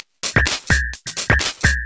Index of /VEE/VEE2 Loops 128BPM
VEE2 Electro Loop 355.wav